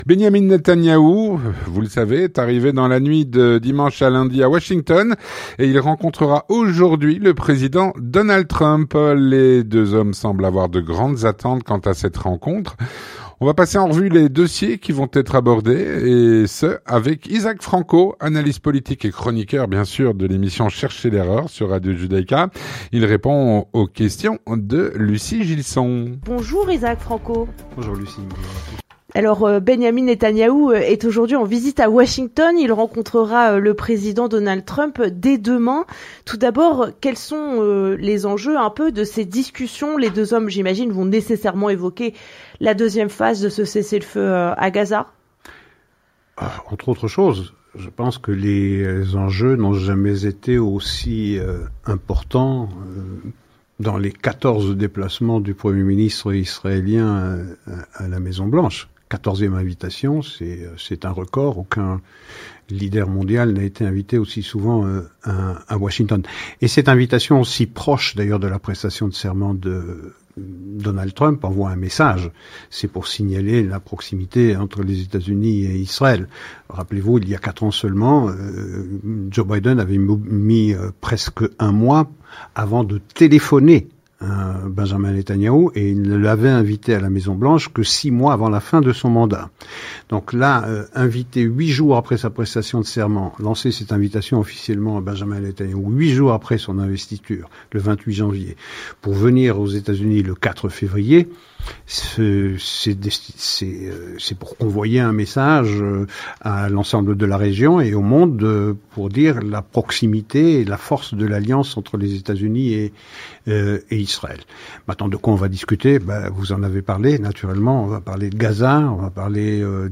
L'entretien du 18H - Benjamin Netanyahou est arrivé à Washington pour rencontrer Donald Trump.